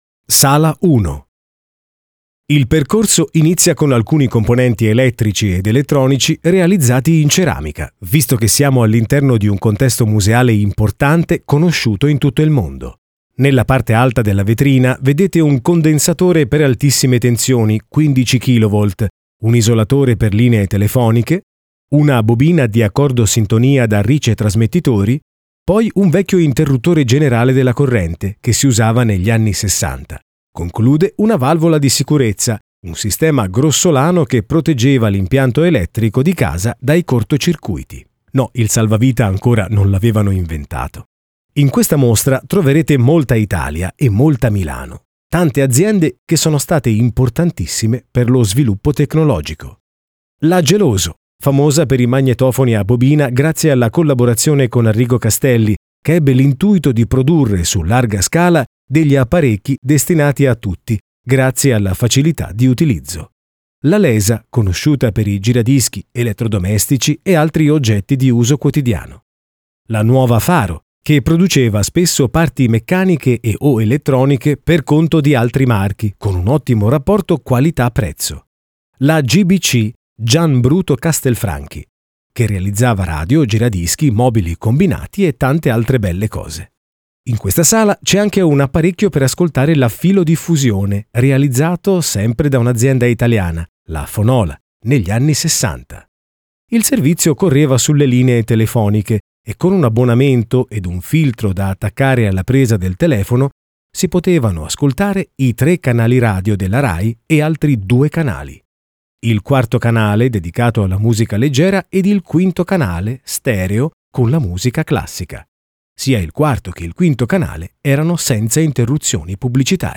Ascolta l’audioguida della mostra al MMAB di Montelupo Fiorentino.